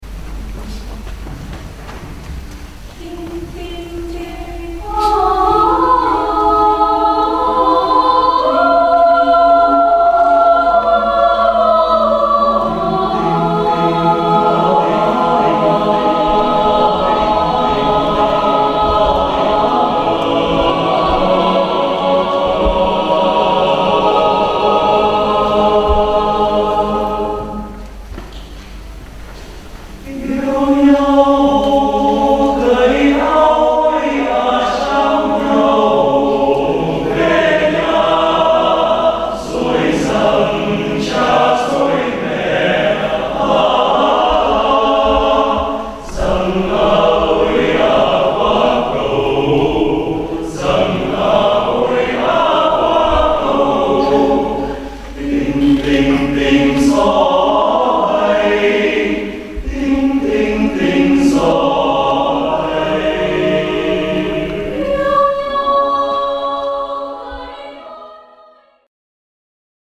Composer: Vietnamese Folk Song
Voicing: SATB a cappella